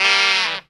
LO HARM FALL.wav